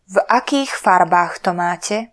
Slovak voice announciation